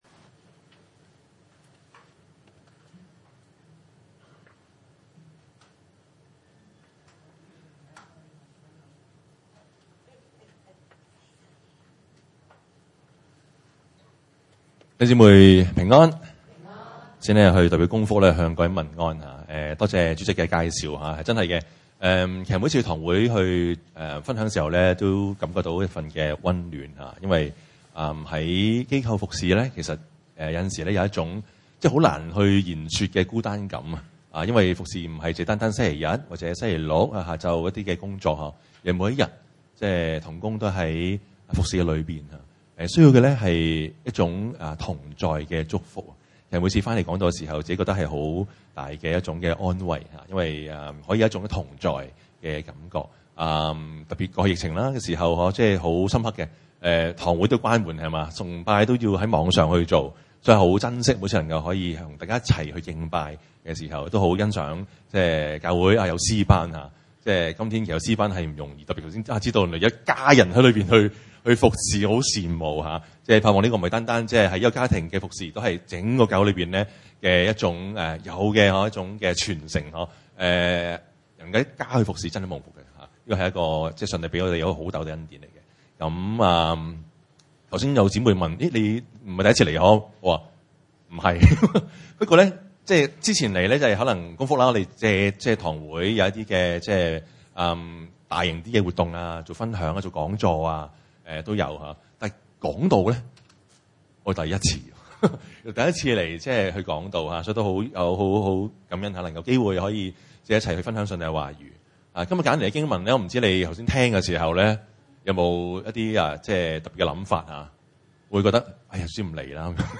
經文: 路加福音10：25-37 崇拜類別: 主日午堂崇拜 25有一個律法師起來試探耶穌，說：夫子！